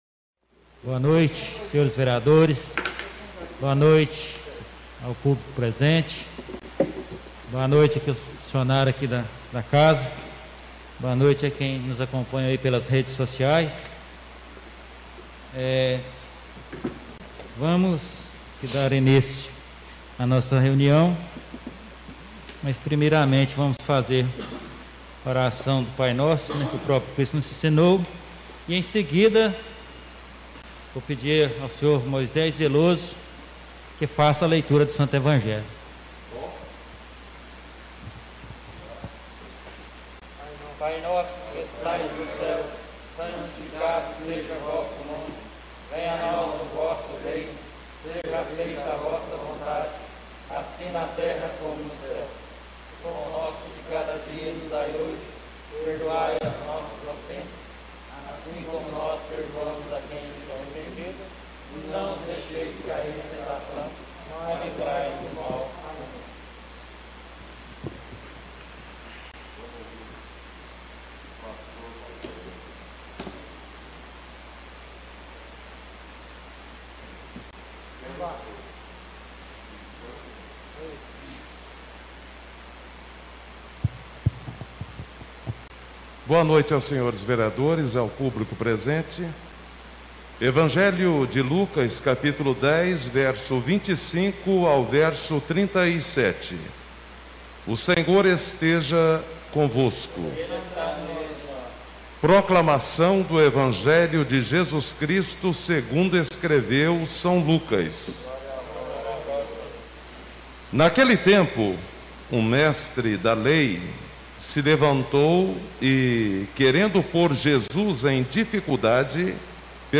Tipo de Sessão: Ordinária